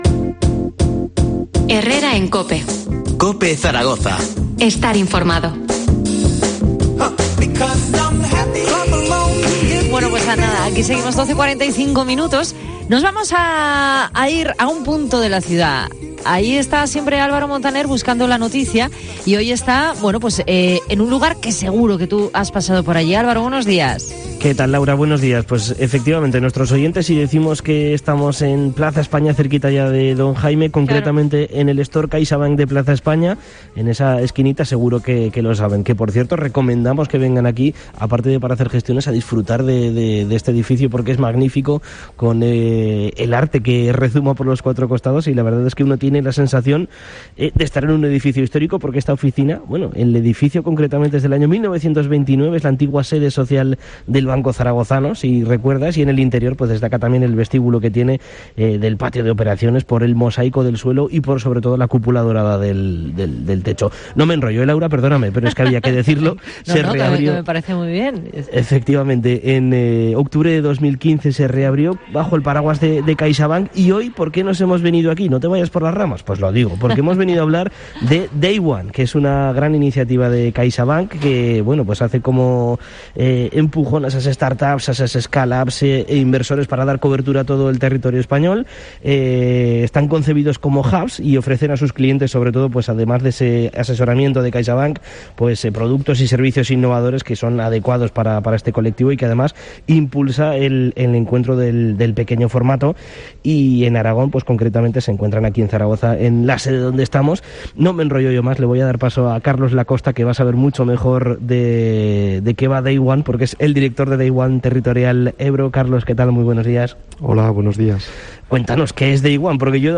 Descubrimos que es 'Day One' desde el Store CaixaBank de Plaza España
Entrevista